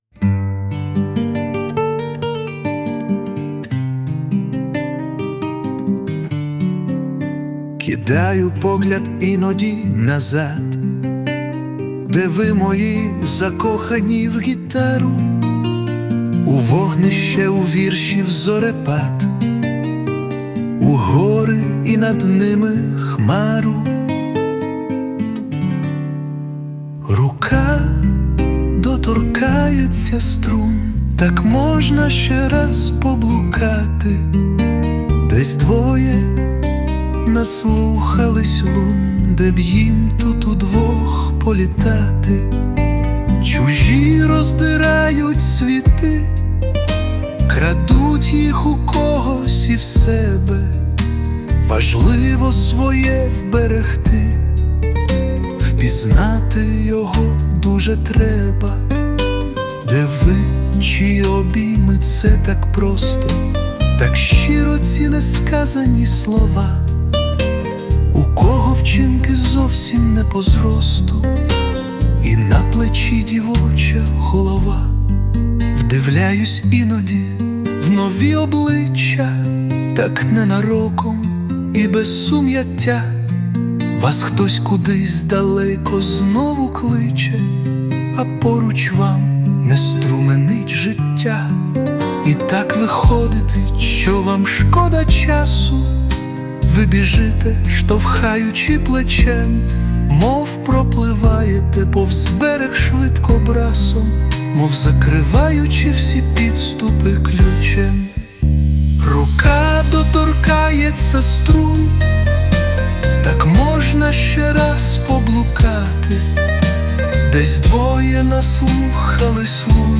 Присутня допомога SUNO
СТИЛЬОВІ ЖАНРИ: Ліричний
Дуже чарівна і ніжна пісня! 16